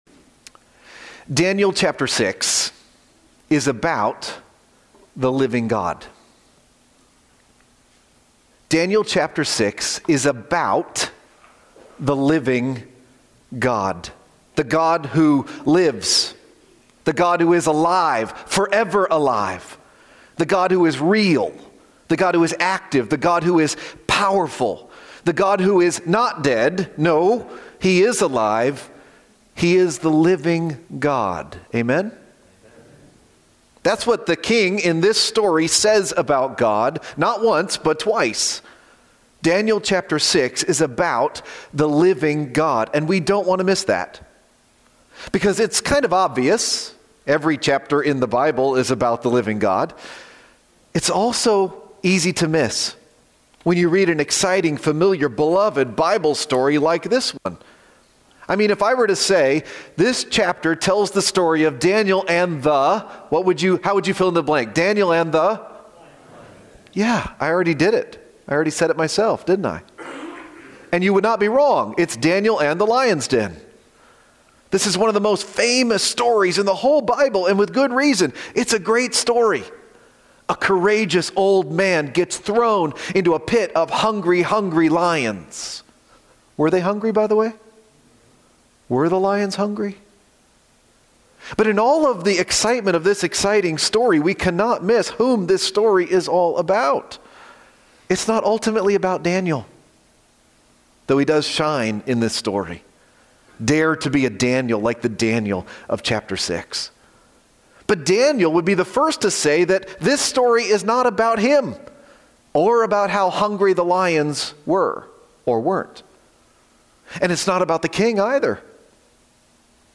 preaching on Daniel 6:1-28
You can listen here or “Right-Click” and “Save-as” to download the sermon: “The Living God”– March 2, 2025